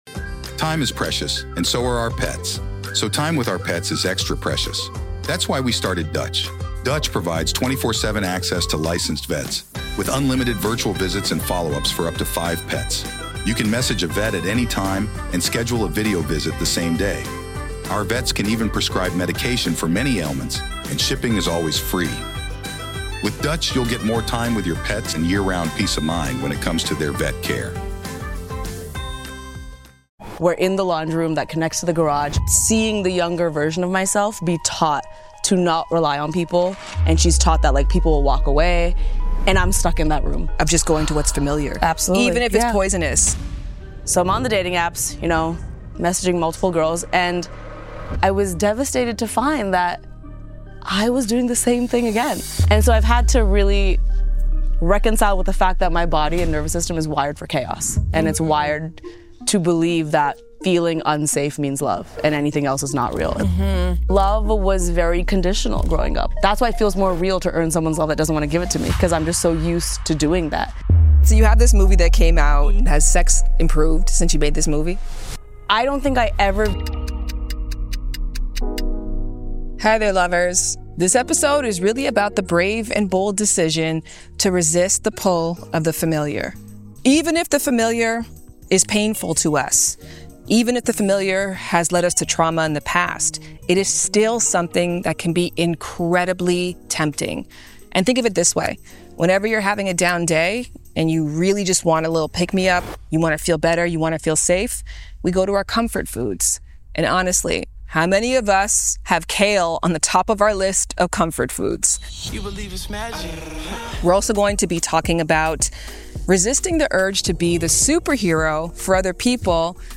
— This week, I interview Lilly Singh , actor and history-making content creator, with a new movie RIGHT up our alley: Doin’ It (yes it’s a movie about sex, specifically what happens when a South Asian virgin is assigned to teach sex ed). In this episode Lilly opens up about growing up in a world where emotional chaos felt familiar, why she kept gravitating toward toxic partners who gave her the bare minimum, and how she’s now doing the inner work (therapy, inner child healing, setting boundaries) to protect “little Lilly.” In this conversation, we explore how past wounds shaped her romantic choices, and how she’s rewriting that story one day at a time.